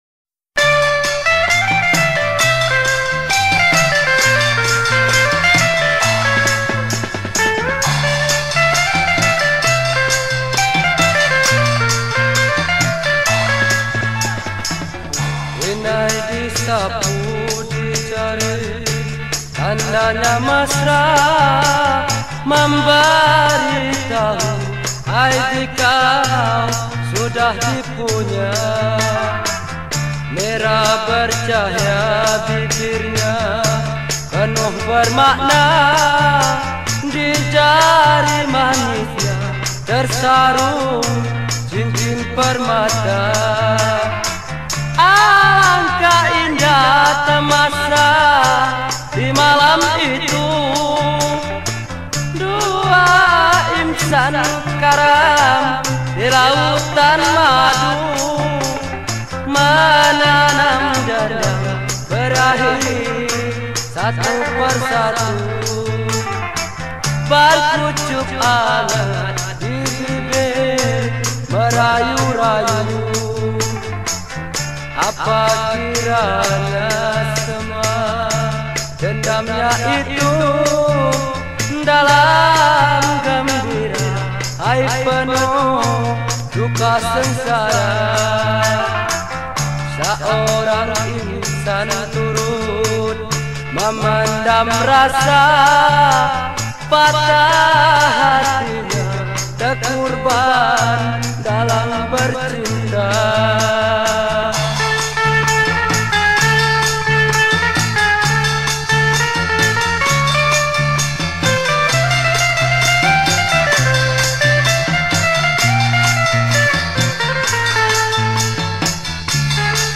Pop Yeh Yeh